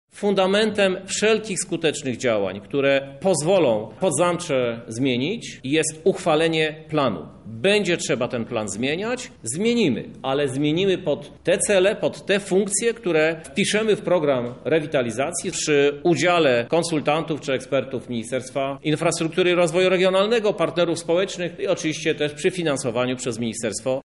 Mówi prezydent Krzysztof Żuk.